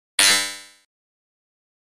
دانلود صدای ربات 32 از ساعد نیوز با لینک مستقیم و کیفیت بالا
جلوه های صوتی